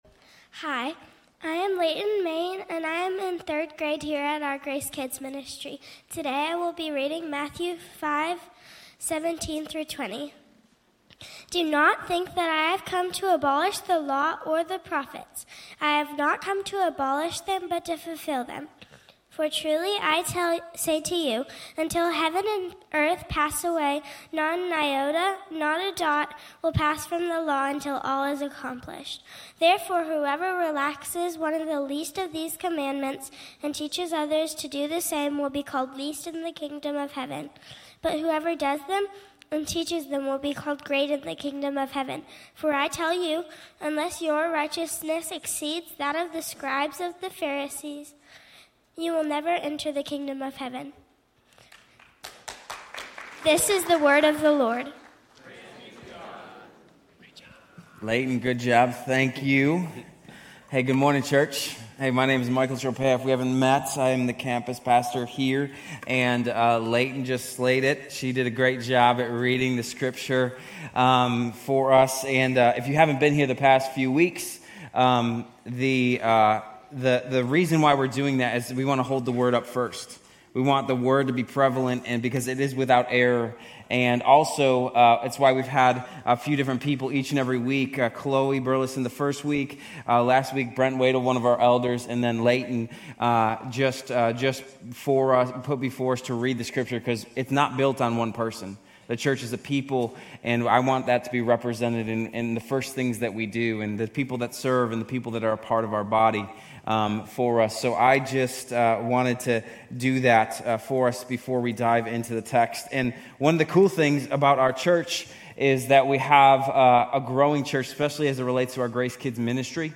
Grace Community Church University Blvd Campus Sermons 2_9 University Blvd Campus Feb 09 2025 | 00:34:48 1x 00:00 / 00:34:48 Subscribe Share RSS Feed Share Link Embed